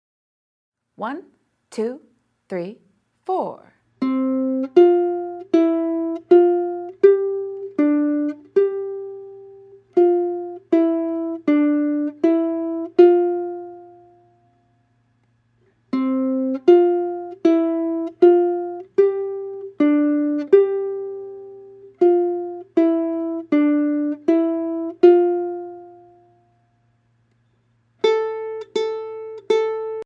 Voicing: Ukulele